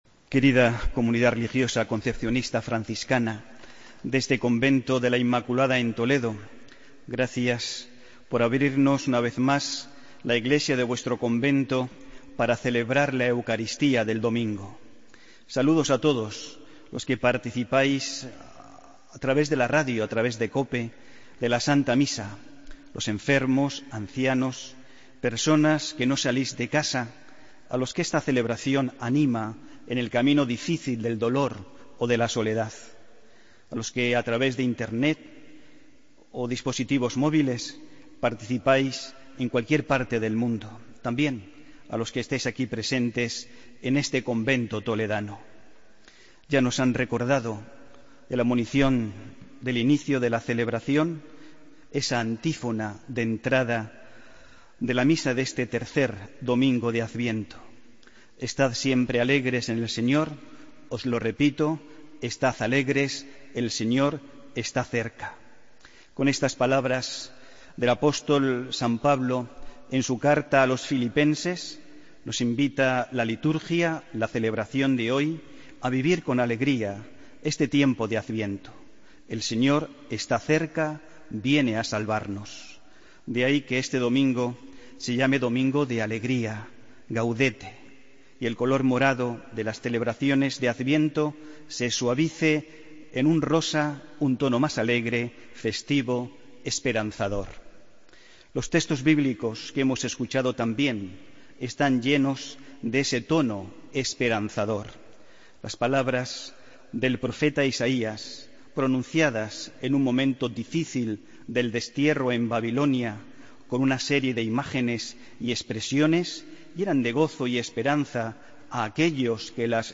AUDIO: Homilía del domingo 11 de diciembre de 2016